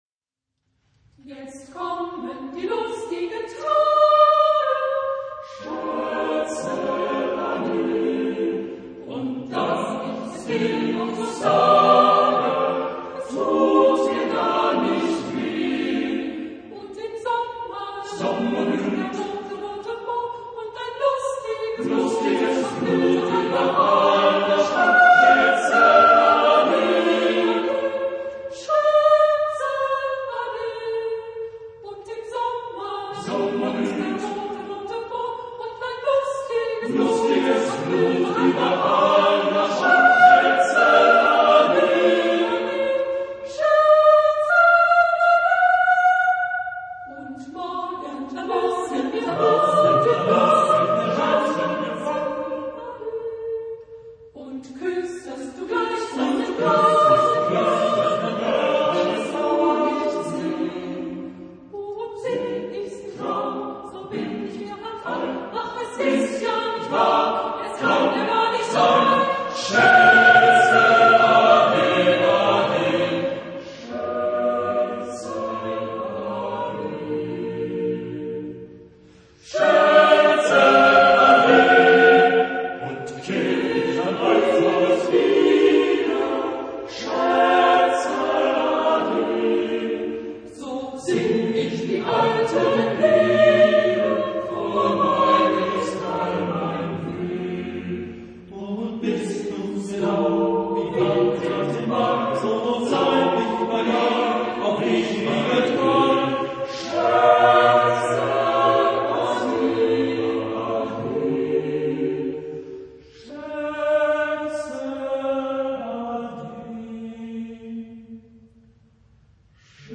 Genre-Style-Form: Folk music ; Travel song ; Partsong ; Secular
Type of Choir: SATB  (4 mixed voices )
Tonality: F major